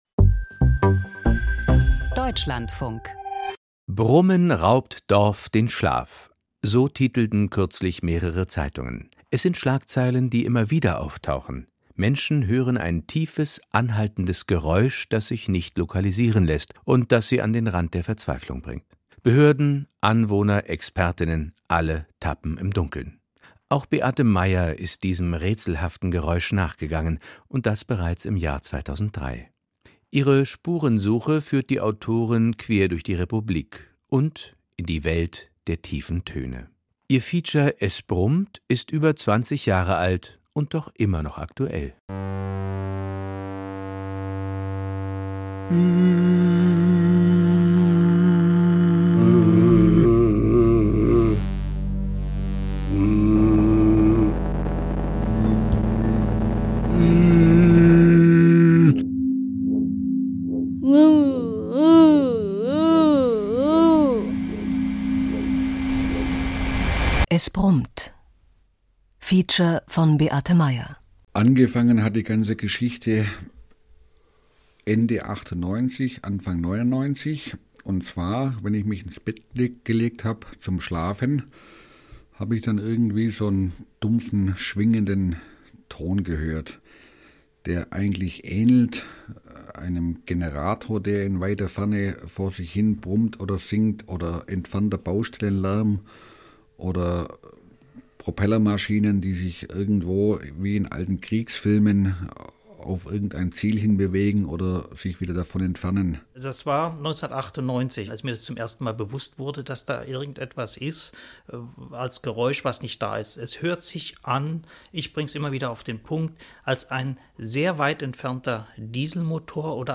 Feature Wenn Eltern von Schulverweigerern das Sorgerecht verlieren Kindesherausgabe 51:43 Minuten Wenn Kinder nicht zur Schule gehen, ist die Kindesherausgabe das letzte Mittel.